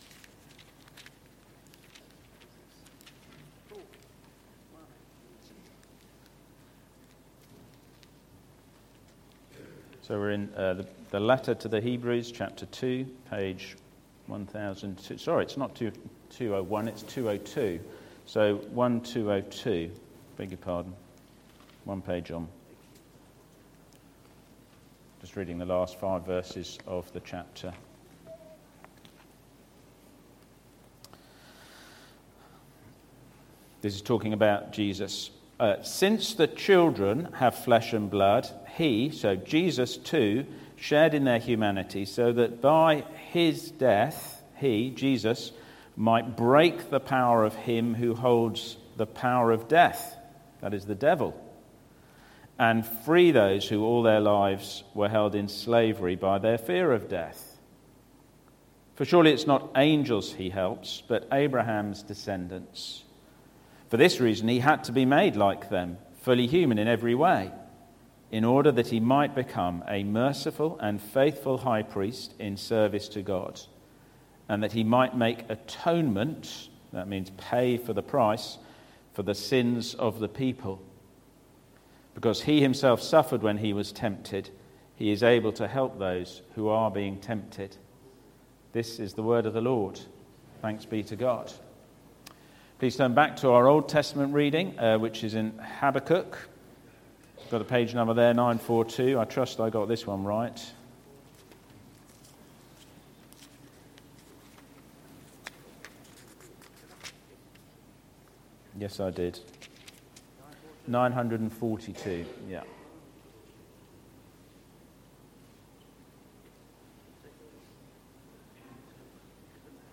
Sermons – Dagenham Parish Church